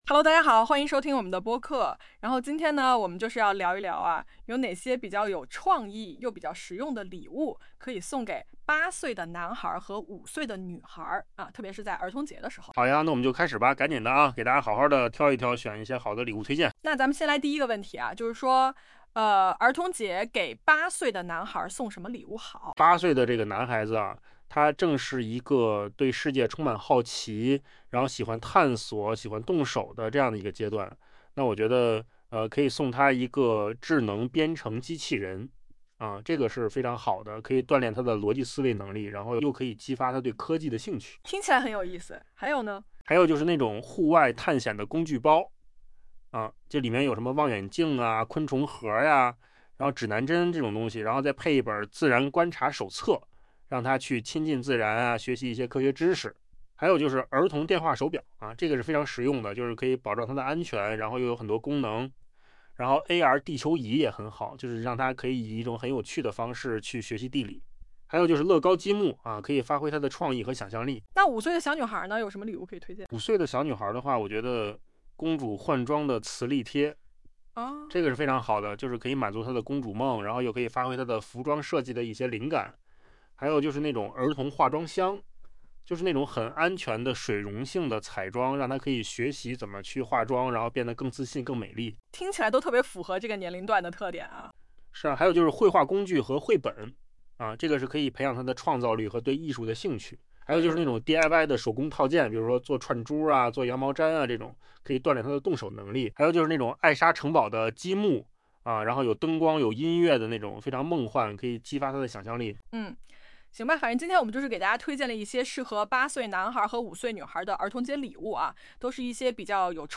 不得不赞叹，扣子空间生成的播客和真人录制的语音效果几乎没有区别了，AI免费打工的图景正成为现实。